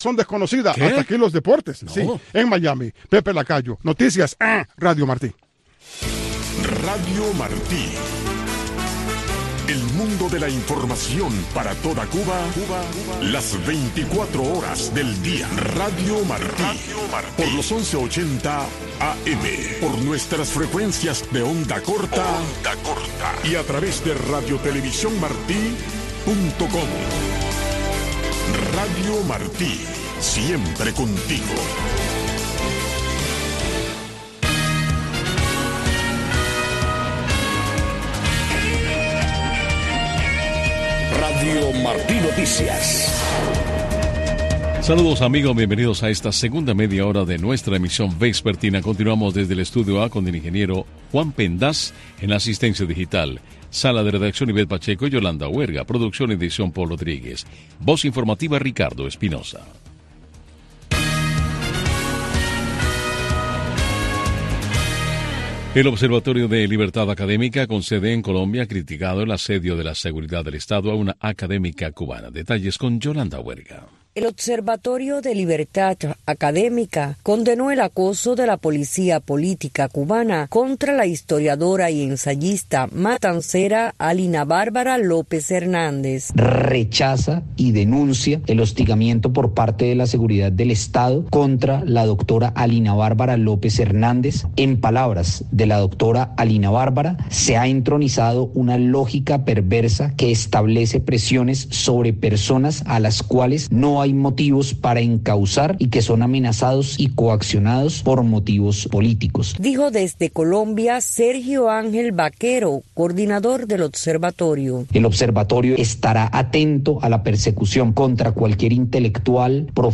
Noticiero de Radio Martí 5:00 PM | Primera media hora